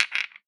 Shell_fall_1.wav